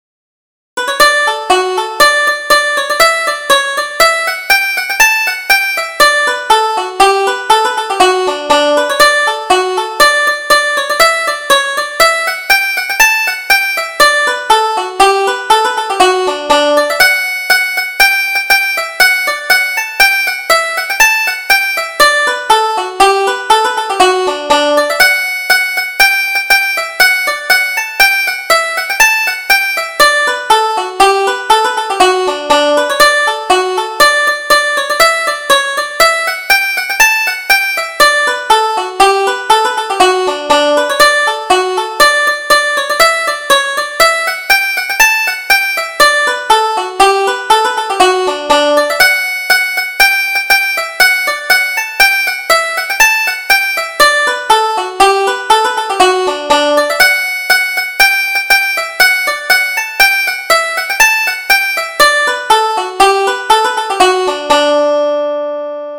Leather Away the Wattle O: Polka
Irish Traditional Polkas